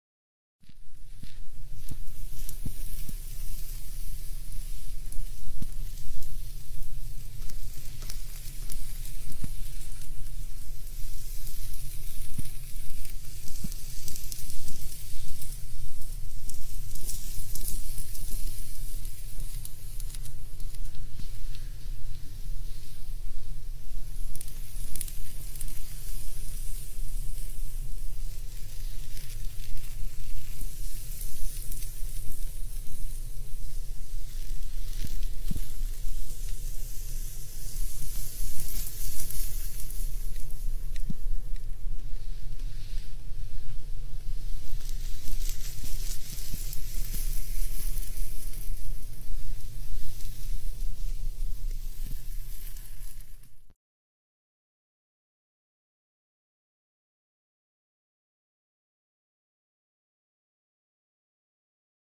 ASMR Hair Play & Echo sound effects free download